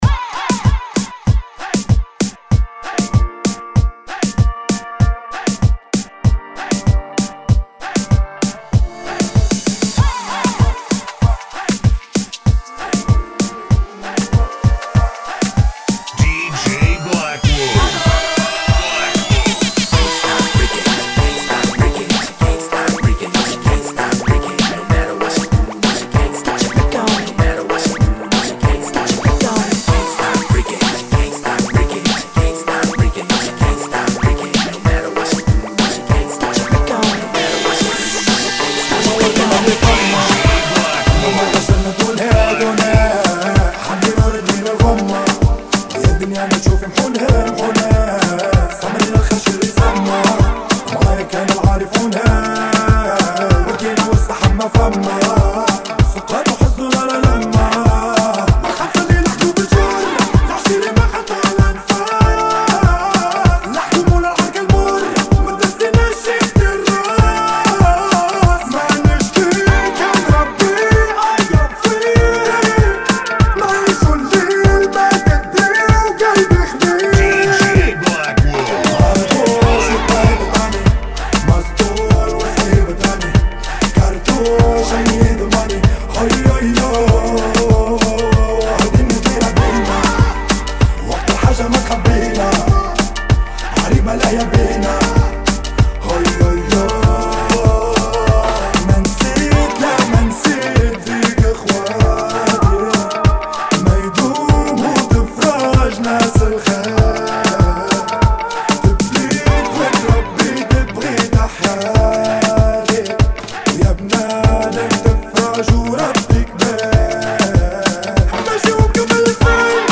[ 96 Bpm ]